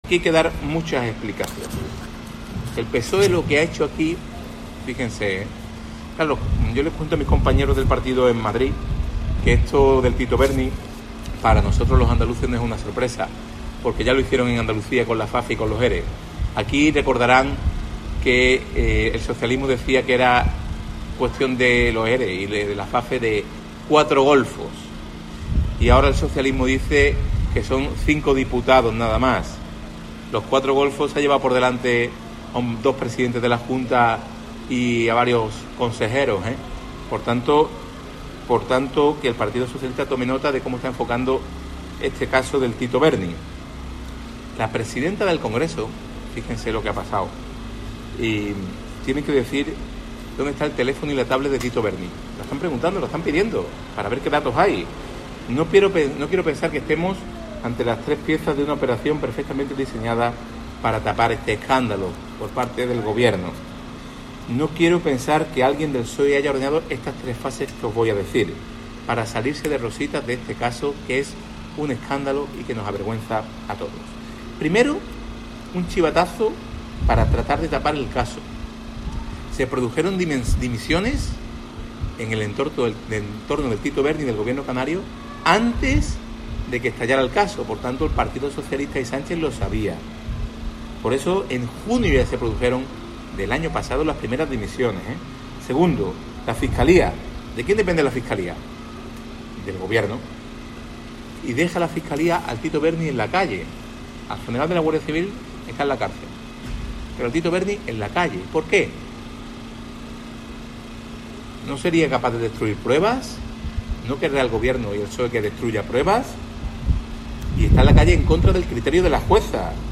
En el transcurso de una intervención abierta a los medios de comunicación durante una visita a una carpa informativa del PP en Málaga, Bendodo ha incidido en reclamar «explicaciones» al PSOE en relación a este caso, porque, según ha apostillado, no quiere «pensar que estemos ante las tres piezas de una operación perfectamente diseñada para tapar este escándalo por parte del Gobierno» de Pedro Sánchez.